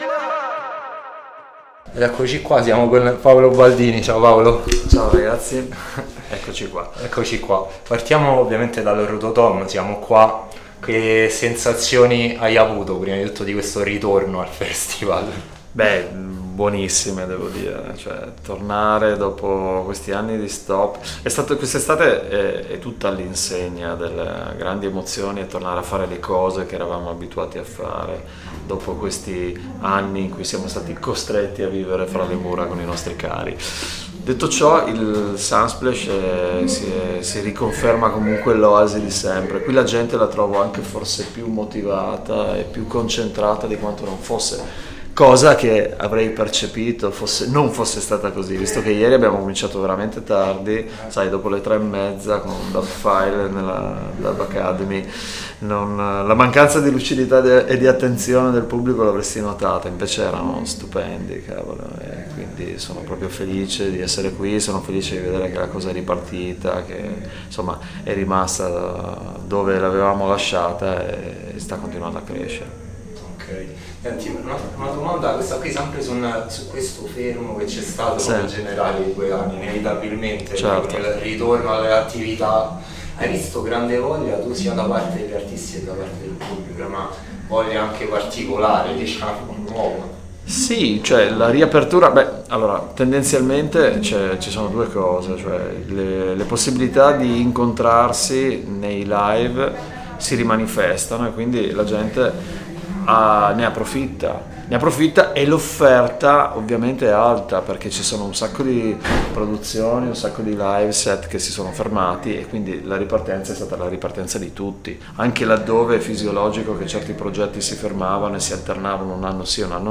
ARTISTA A LA VISTA | INTERVISTA
live dal festival Rototom Sunsplash. Abbiamo parlato del nuovo album con gli Almamegretta, il viaggio che lo ha portato in Jamaica, e le collaborazioni e connessioni con moltissimi artisti, fino alle impressioni sul Reggae in Italia e l’amicizia con i Mellow Mood.